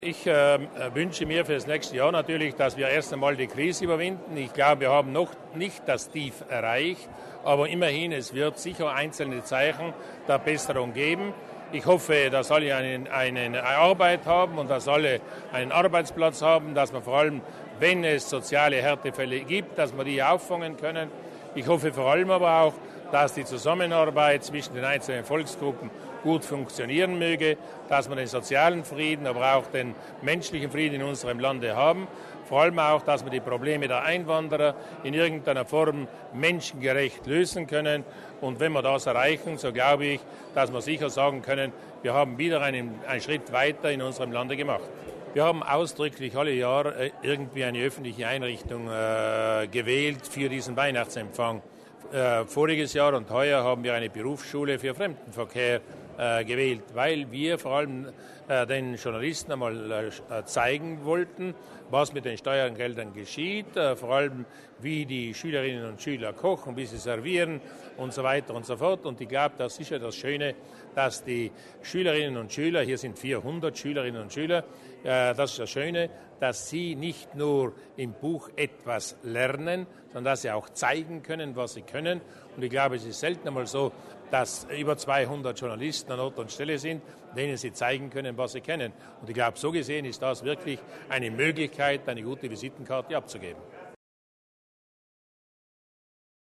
Weinachtsempfang für Presse: O-Ton von Landeshauptmann Luis Durnwalder